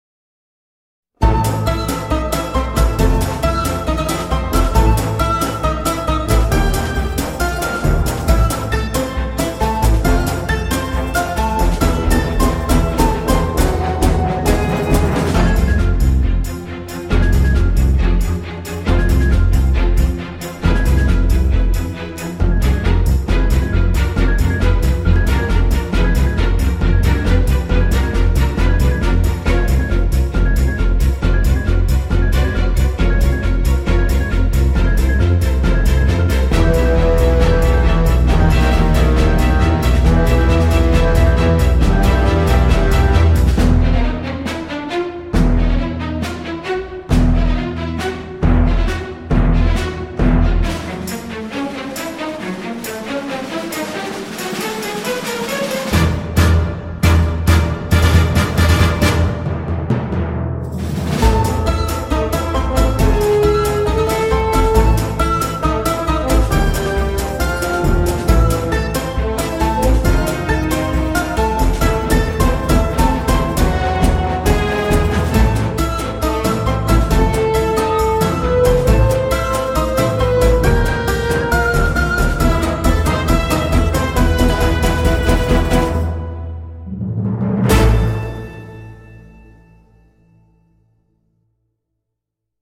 dulcimer et balalaïkas, percussions autoritaires
très rythmique
relevée par la cithare
cymbalum, balalaïka, flûte de pan